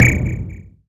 whistle1.ogg